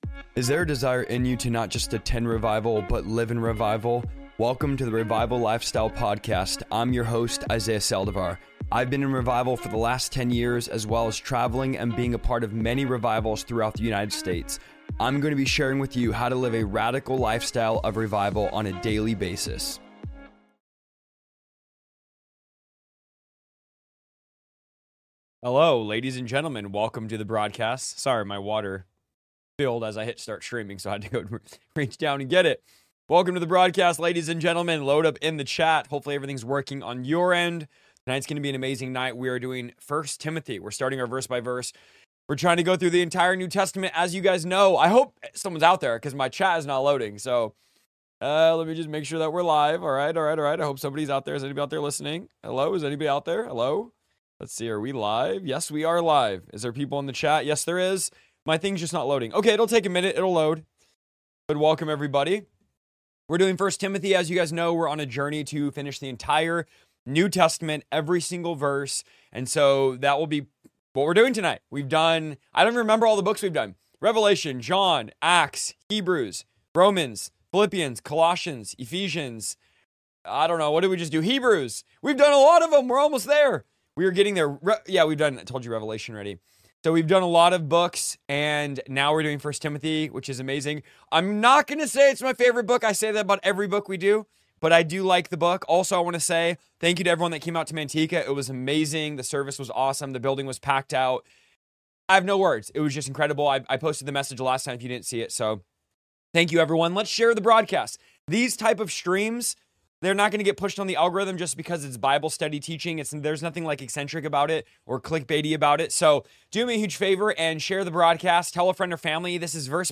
Join me for a powerful verse-by-verse breakdown of 1 Timothy chapters 1 and 2, where the Apostle Paul gives bold instructions to his spiritual son, Timothy. In this livestream, we’ll explore: